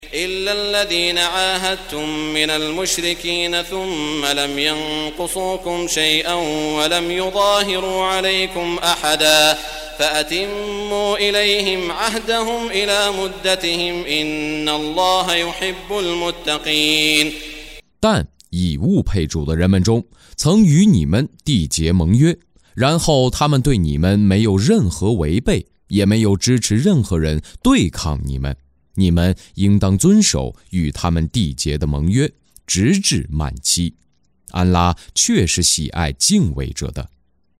中文语音诵读的《古兰经》第（讨拜）章经文译解（按节分段），并附有诵经家沙特·舒拉伊姆的朗诵